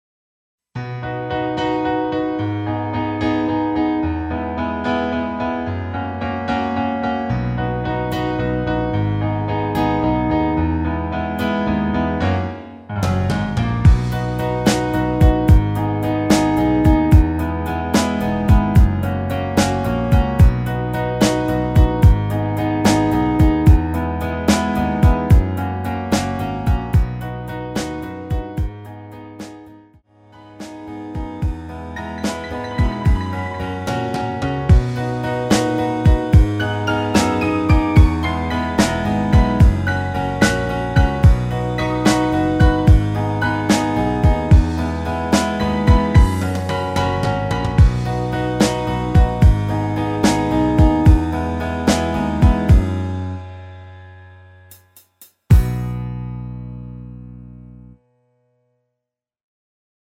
엔딩이 페이드 아웃이라 노래 하시기 좋게 엔딩을 만들어 놓았습니다.(미리듣기 참조)
앞부분30초, 뒷부분30초씩 편집해서 올려 드리고 있습니다.